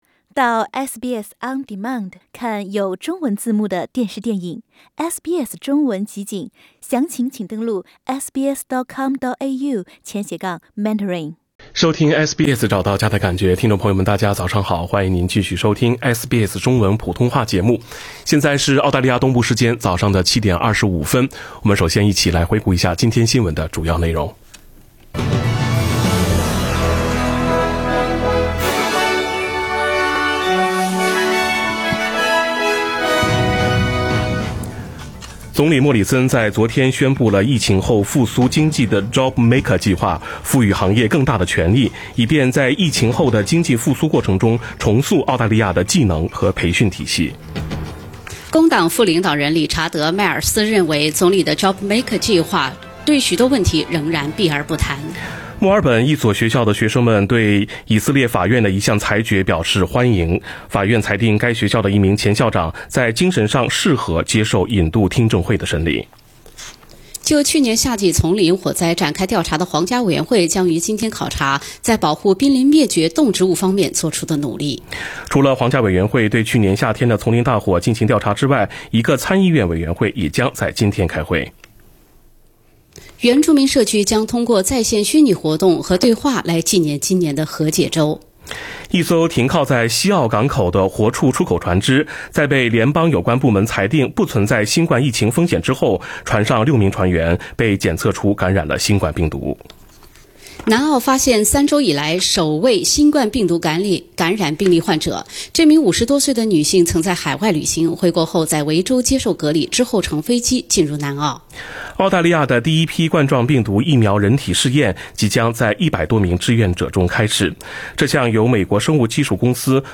SBS早新闻（5月27日）